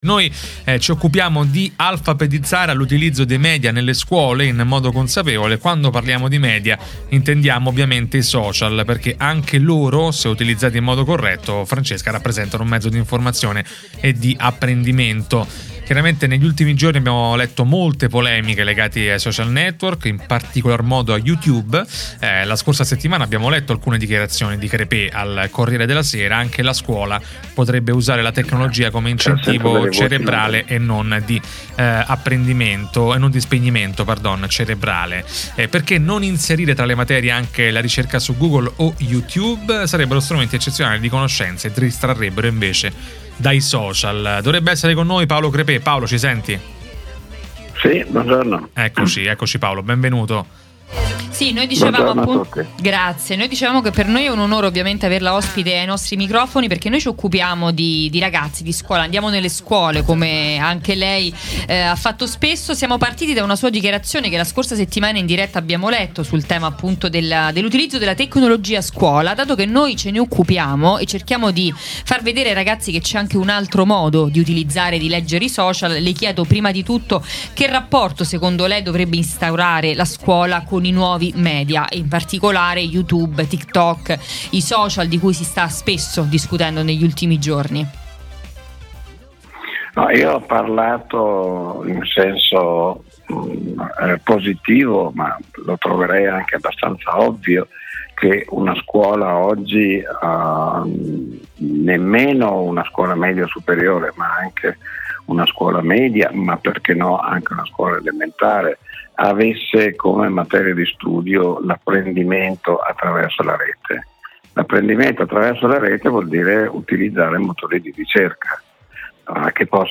psichiatra, sociologo, educatore e saggista italiano, con noi per parlare di scuola, genitori, studenti e social network